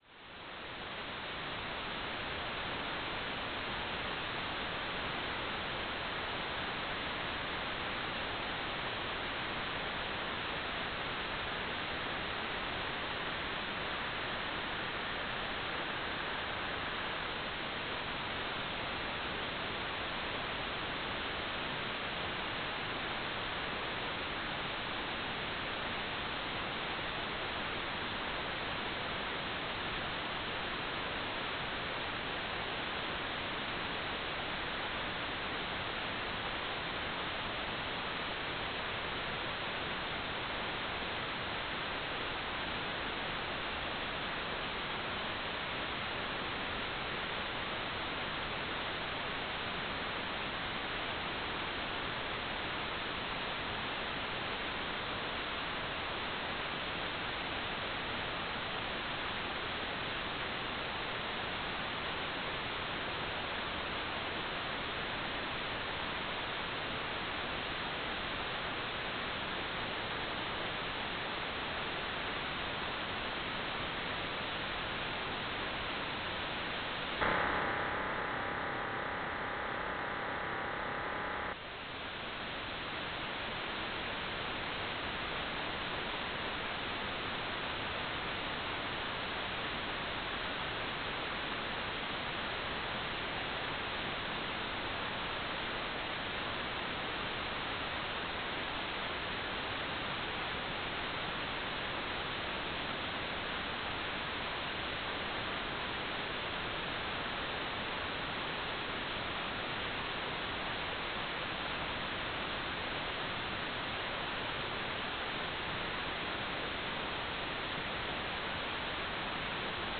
"waterfall_status": "without-signal",
"transmitter_description": "CW TLM",
"transmitter_mode": "CW",